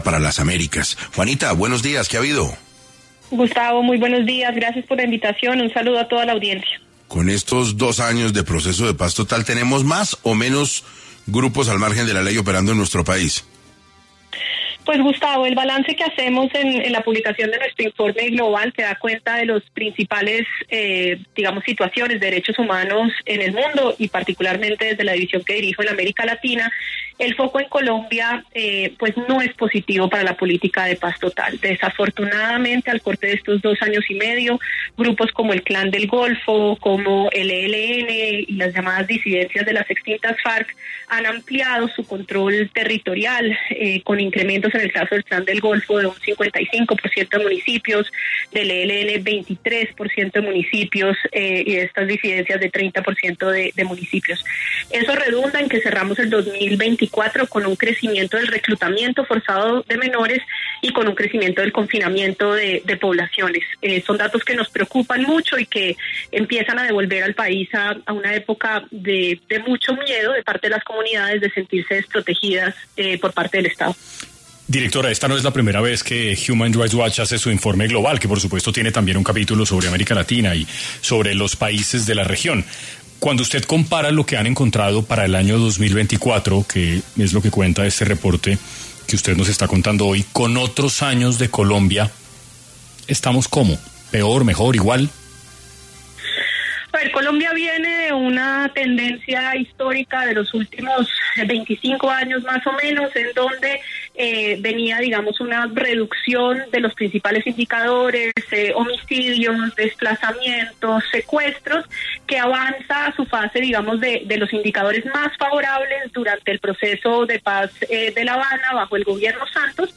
En entrevista con 6AM de Caracol Radio